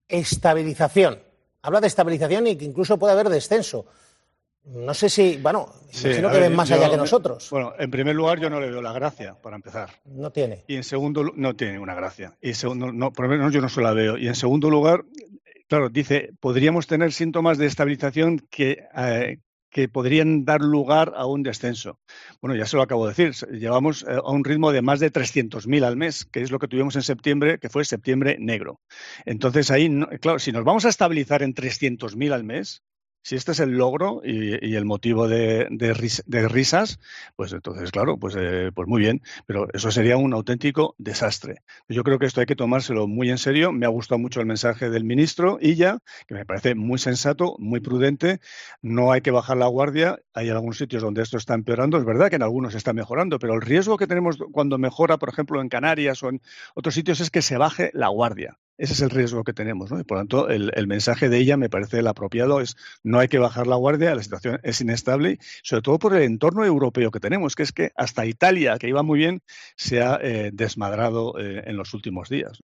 Enfado de Miguel Sebastián con Fernando Simón